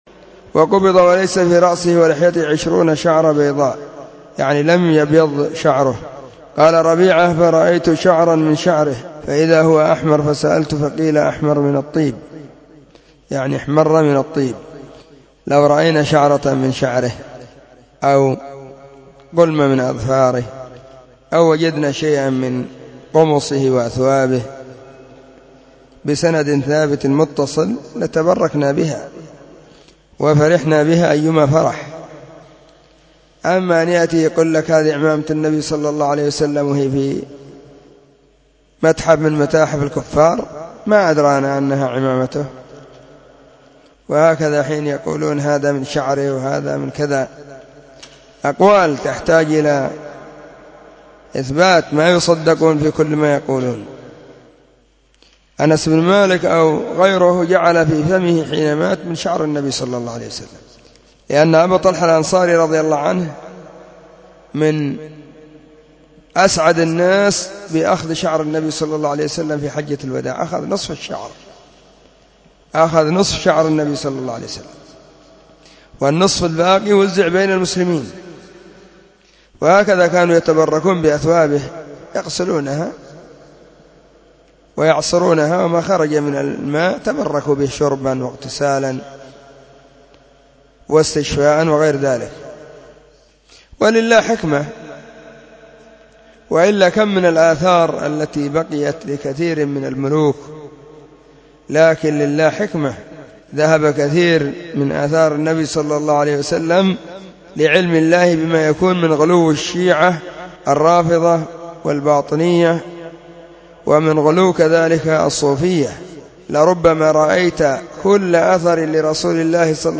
📢 مسجد الصحابة بالغيضة, المهرة، اليمن حرسها الله.